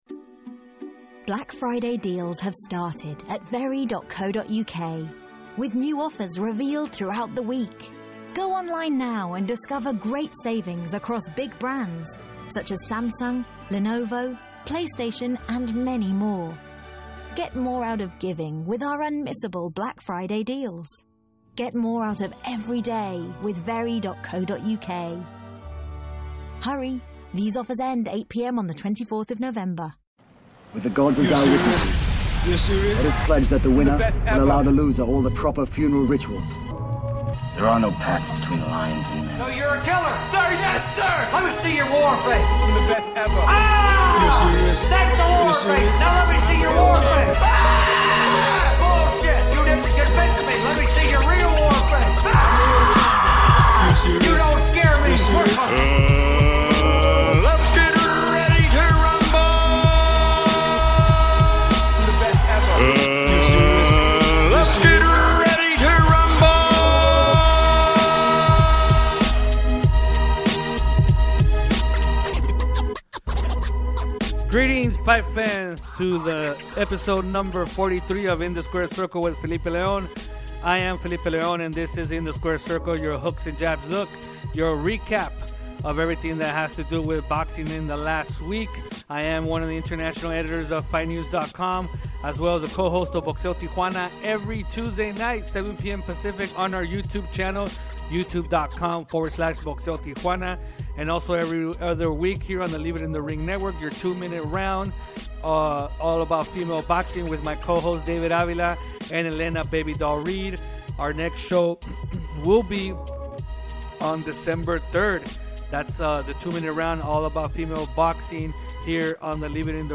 with a fast pace style of 30 minutes or less